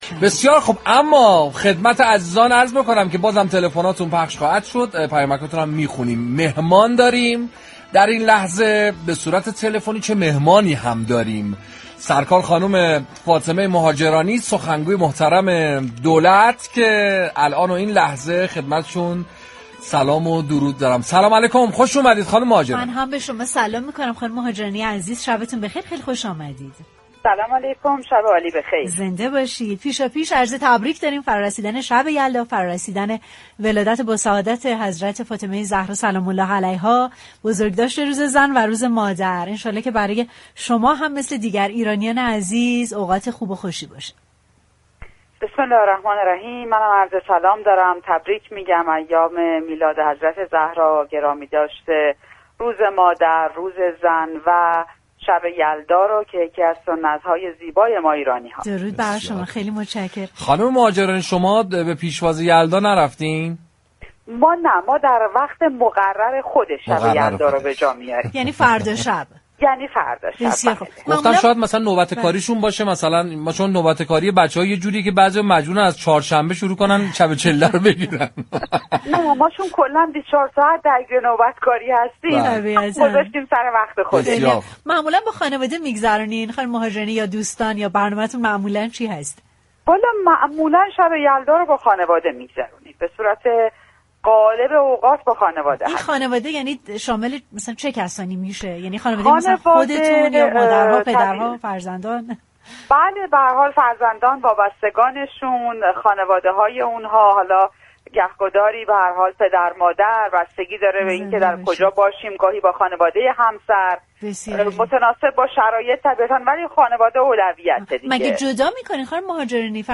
فاطمه مهاجرانی در گفتگو با رادیو تهران بر دغدغه رئیس جمهور نسبت به معیشت مردم تاكید كرد و گفت: من عهد كرده‌ام سخنگوی دولت و زبان مردم باشم.